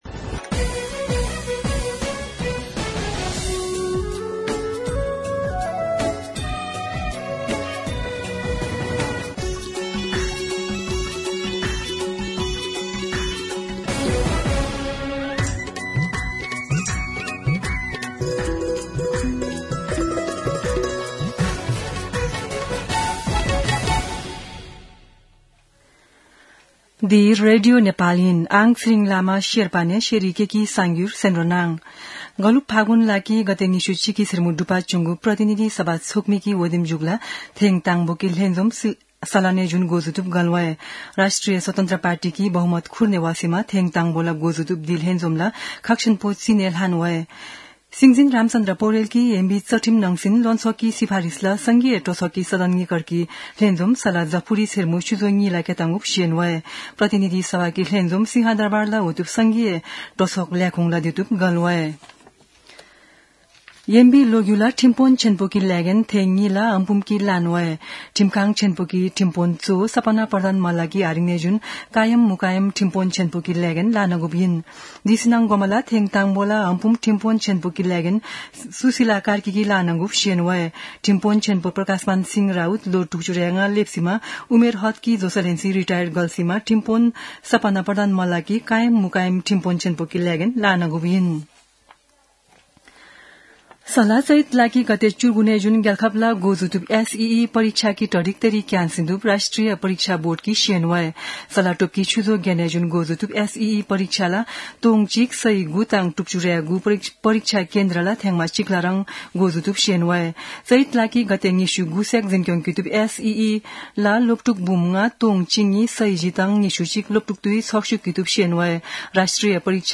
शेर्पा भाषाको समाचार : १८ चैत , २०८२
Sherpa-News.mp3